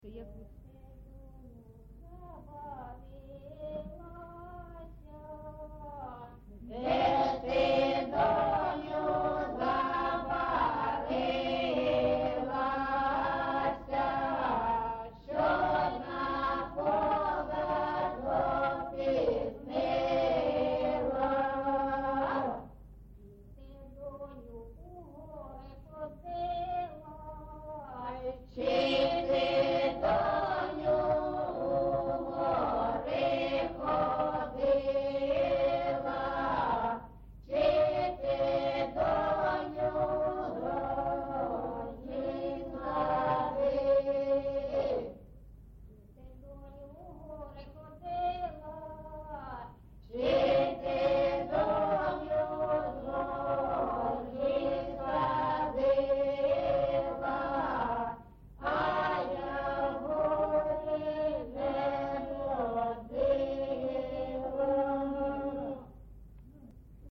ЖанрВесільні
Місце записус. Маринівка, Шахтарський (Горлівський) район, Донецька обл., Україна, Слобожанщина